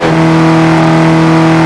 mr2gt_revdown.wav